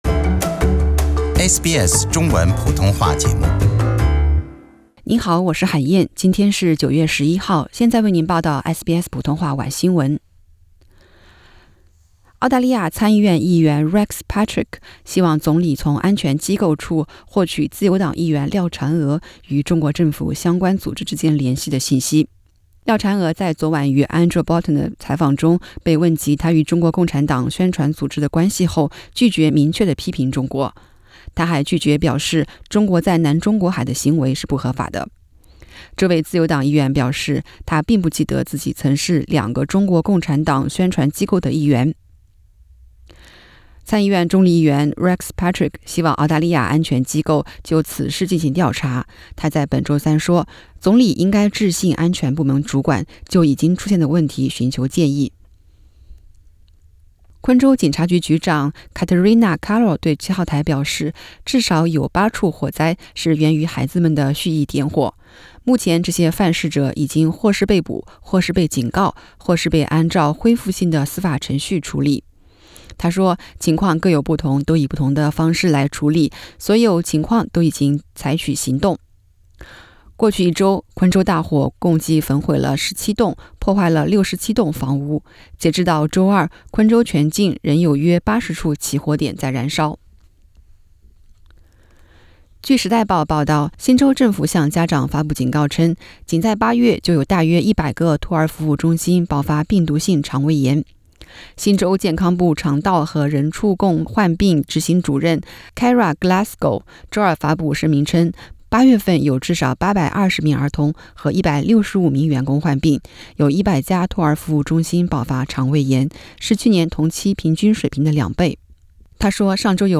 SBS晚新闻（9月11日）